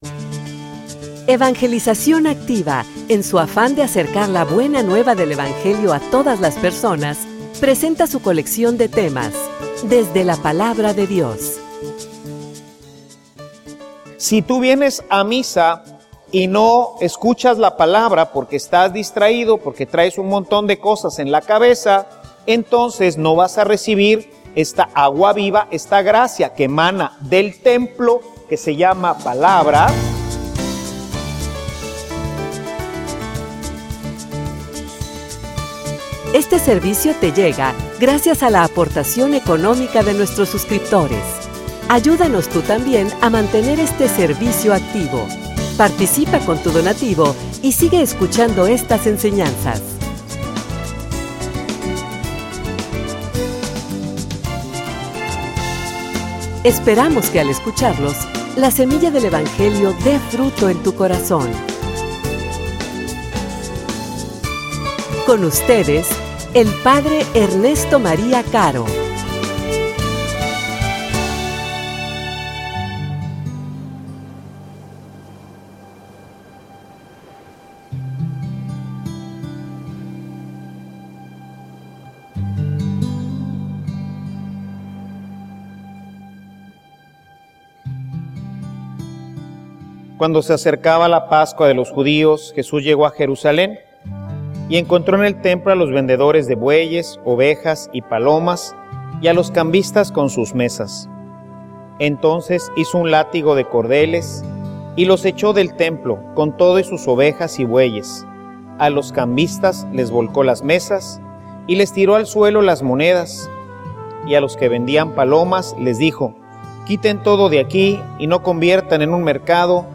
homilia_El_templo.mp3